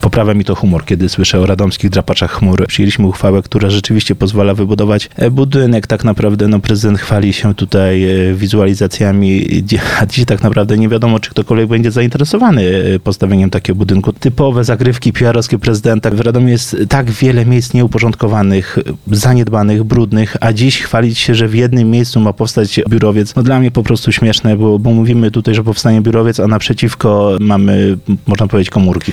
Mówi szef klubu radnych PIS, Łukasz Podlewski w Mocnej Rozmowie: